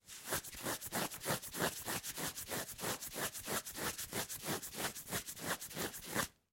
Звуки чесания
Звук скребущего по одежде человека